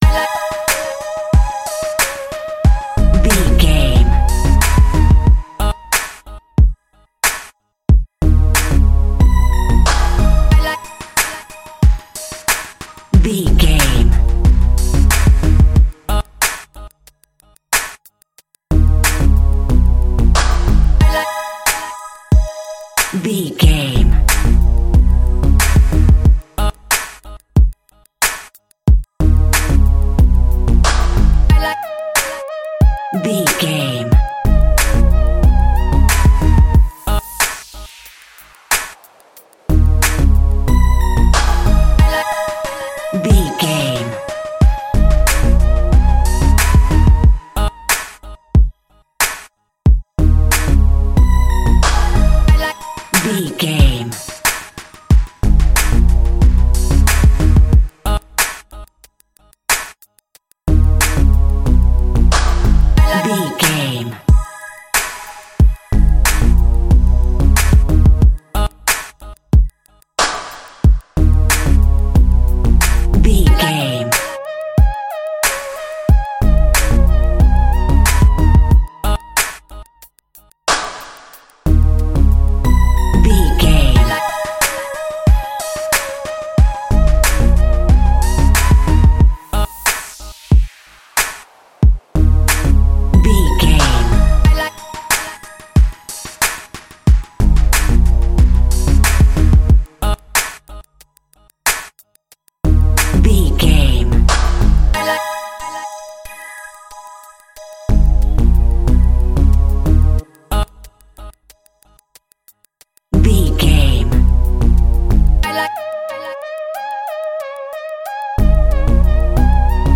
Aeolian/Minor
A♭
synthesiser
90s